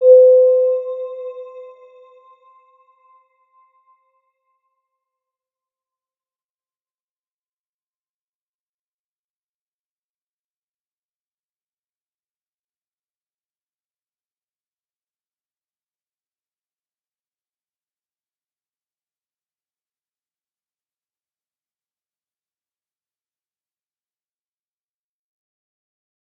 Round-Bell-C5-p.wav